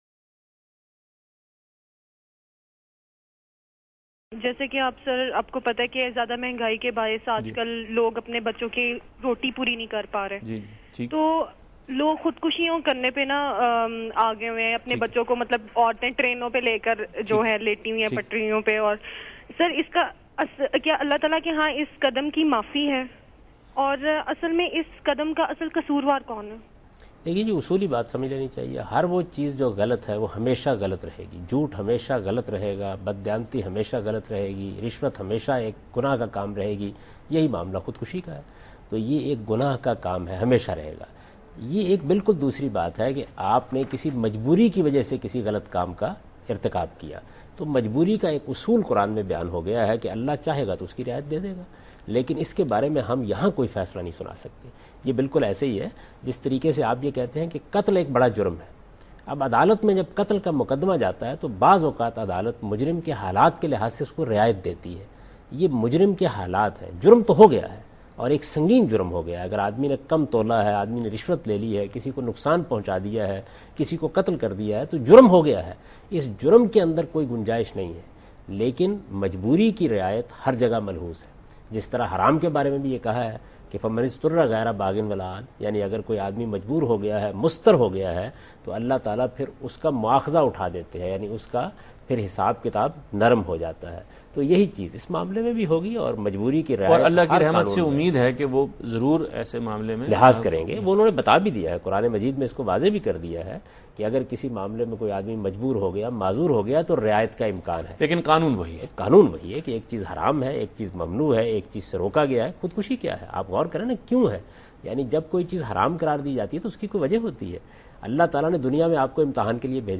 Category: TV Programs / Dunya News / Deen-o-Daanish /
Is Suicide Haram: Ghamidi talks about that